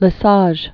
(lə-säzh), Alain René 1668-1747.